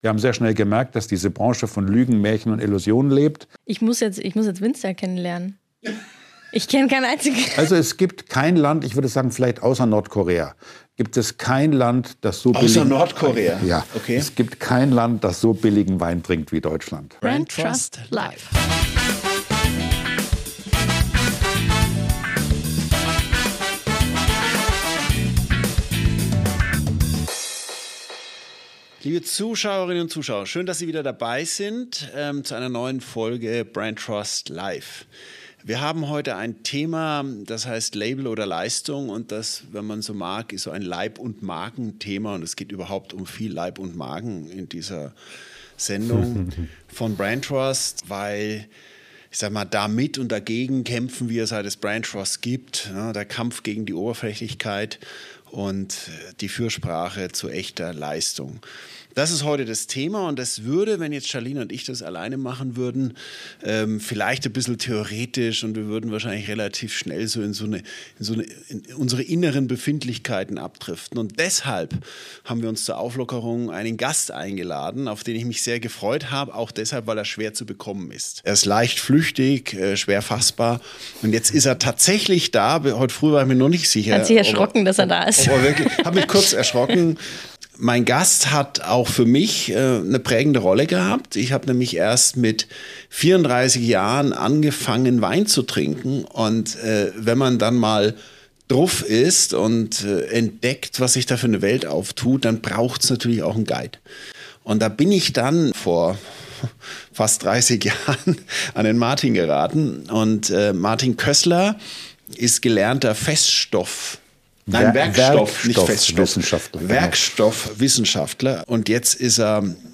BrandTrust Life - ein Gespräch zwischen zwei Markenexperten, die unterschiedlicher nicht sein könnten: